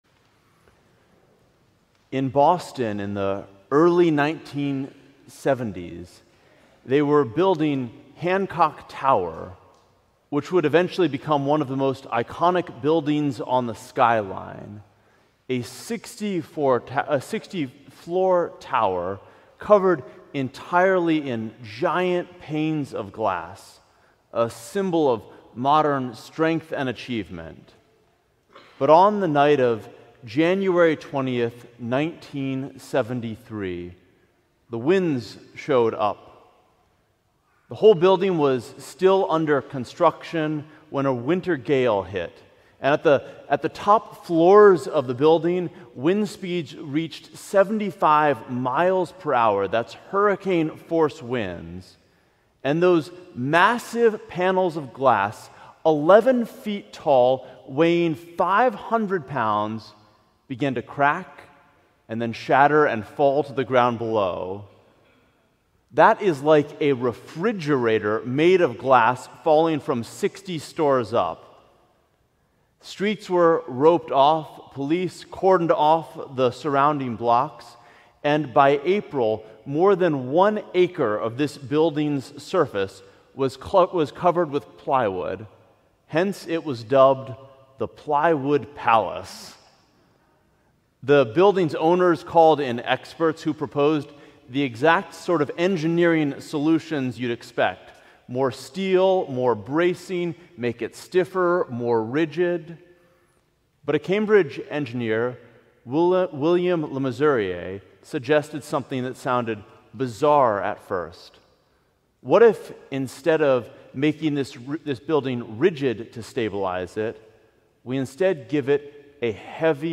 Sermon: Our Baptismal Core